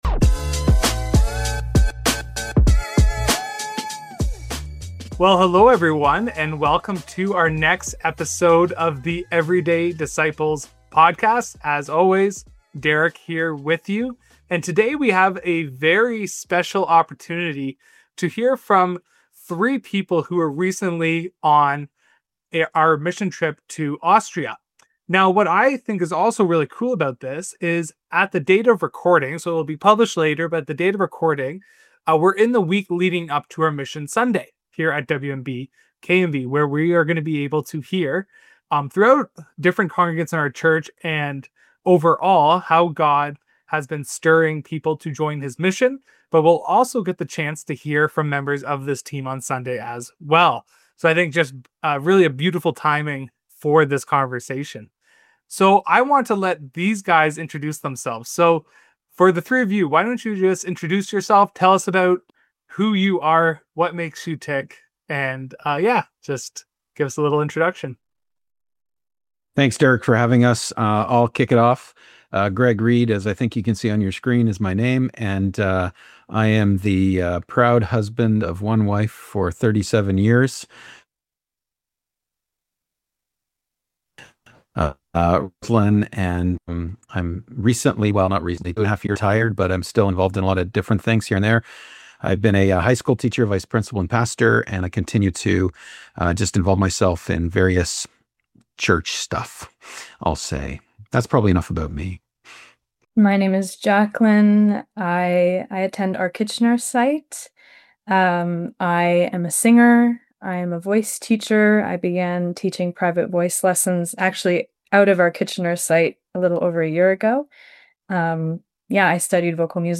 In this episode of our Everyday Disciples Podcast, we sit down with members of our Austria Missions Team to hear how God invited them to step into His global mission.